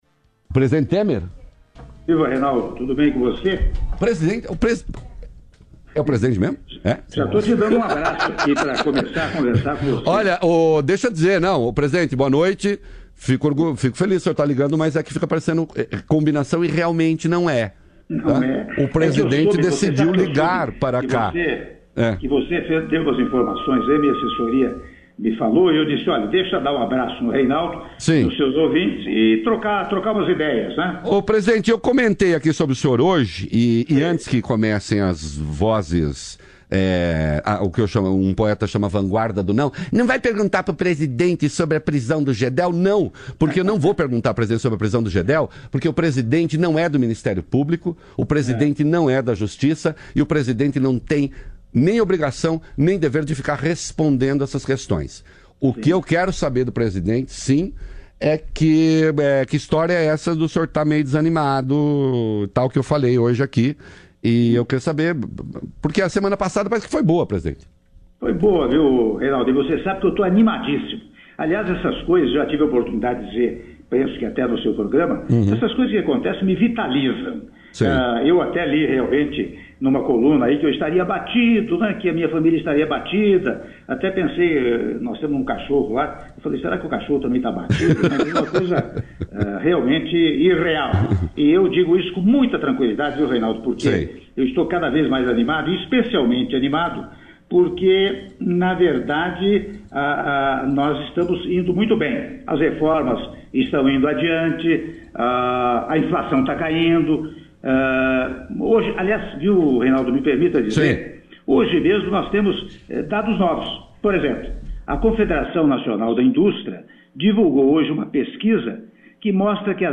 Áudio da entrevista exclusiva concedida pelo Presidente da República, Michel Temer, à Rádio BandNews - (05min35s) - Brasília/DF